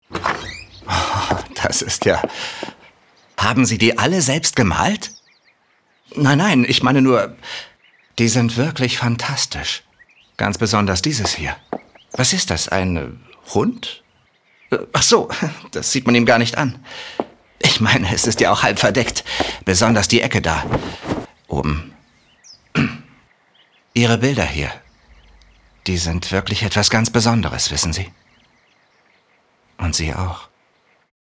sehr variabel
Mittel minus (25-45)
Lip-Sync (Synchron)